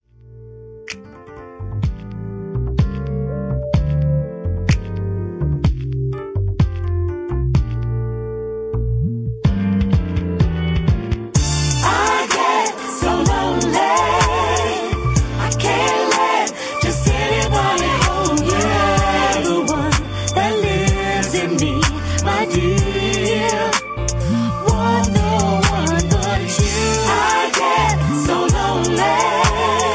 Key-Dm) Karaoke MP3 Backing Tracks
Just Plain & Simply "GREAT MUSIC" (No Lyrics).